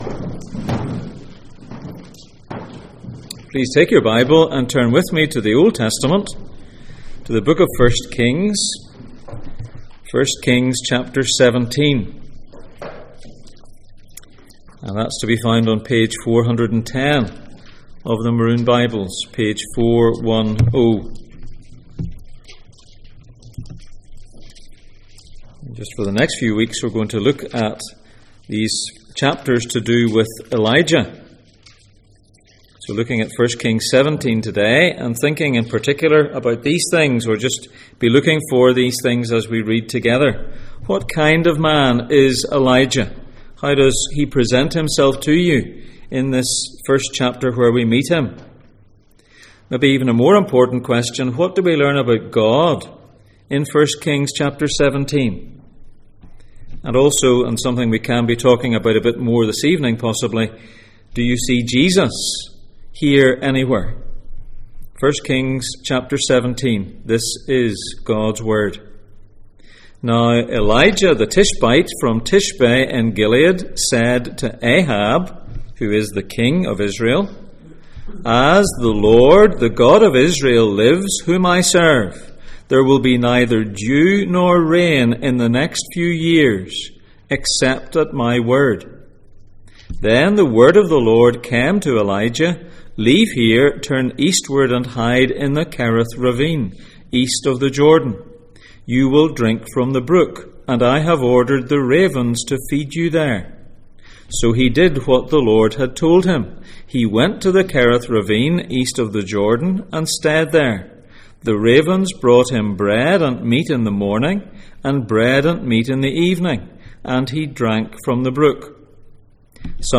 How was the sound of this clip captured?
Elijah Passage: 1 Kings 17:1-24, 1 Kings 16:33, Ephesians 1:22, Ephesians 3:20 Service Type: Sunday Morning